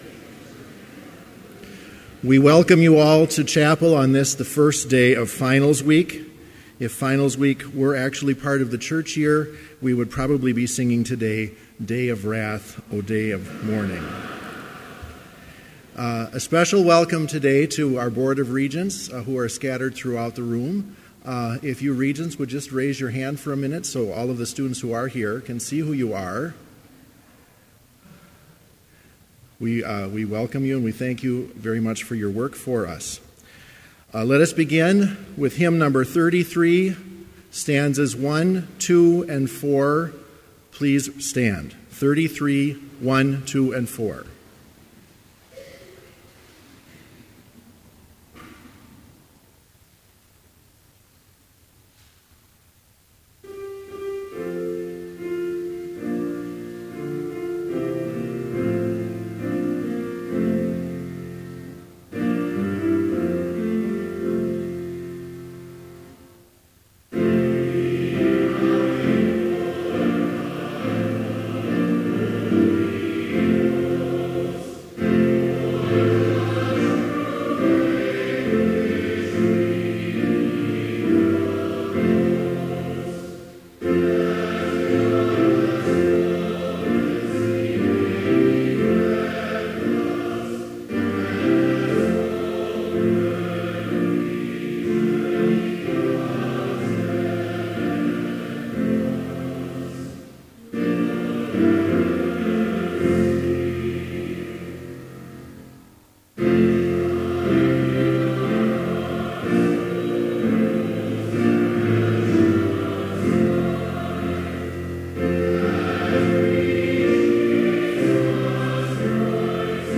Complete service audio for Chapel - May 11, 2015